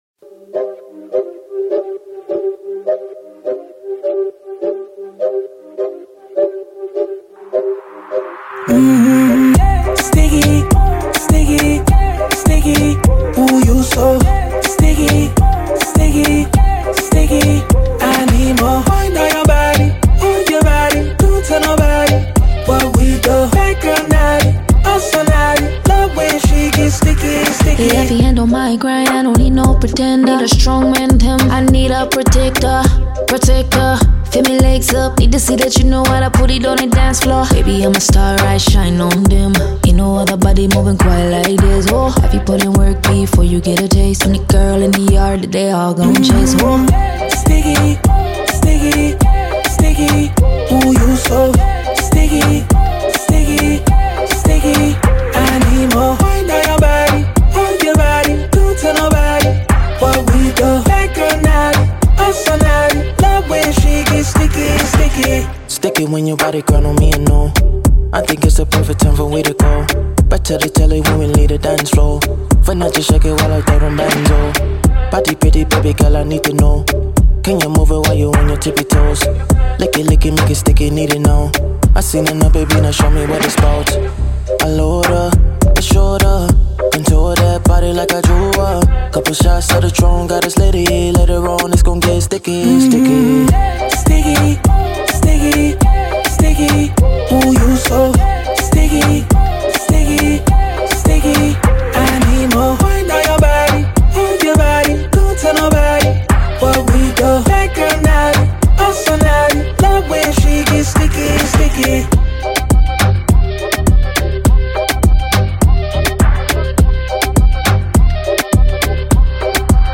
Afrobeats, Reggae, R&B
Cm
Afro club party flirty vibes